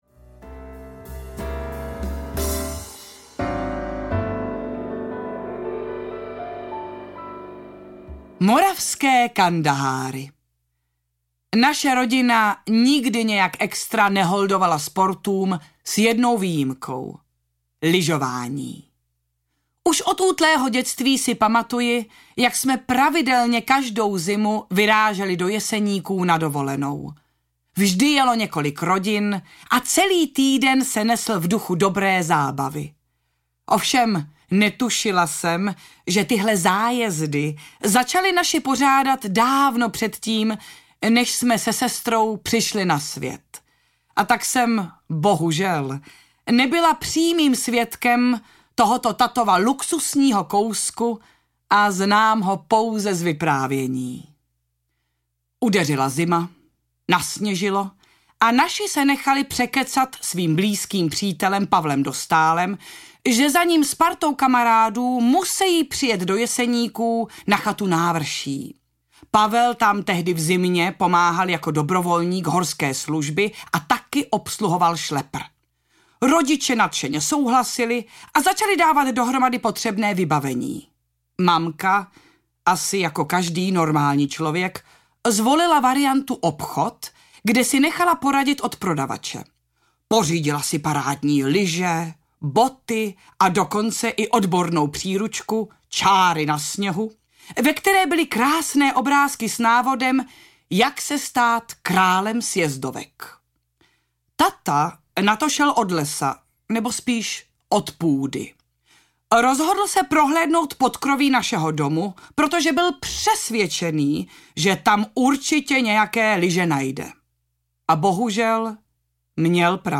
Hoď se do Pogody audiokniha
Ukázka z knihy